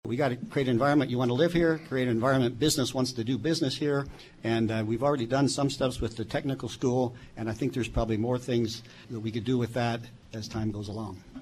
During this past weekend’s League of Women Voters Forum on KMAN, candidates were asked what the city’s role should be in spurring development of jobs that provide a livable wage.
Seven took part in the forum Saturday at the Manhattan Public Library, hosted by the League and co-sponsored by the local chapter of the American Association of University Women and the Manhattan Area Chamber of Commerce.